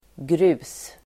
Uttal: [gru:s]